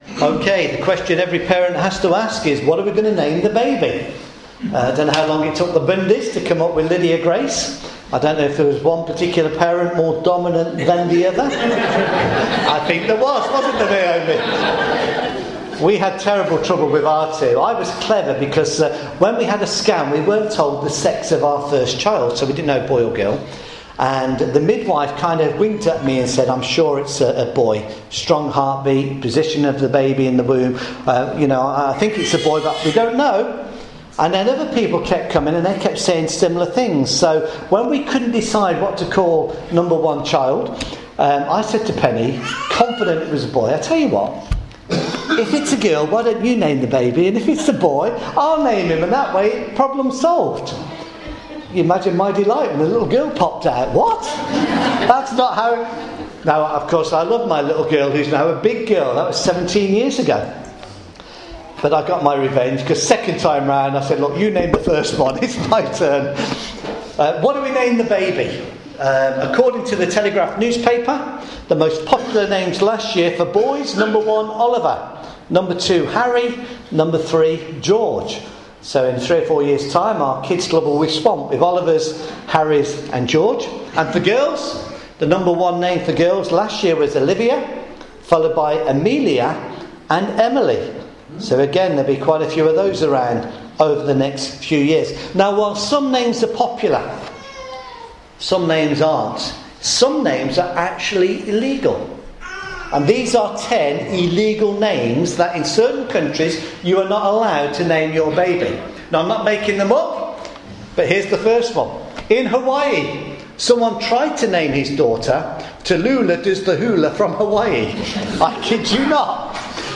Session 1: HOPE – Romans chapter 15 verse 13 – sermon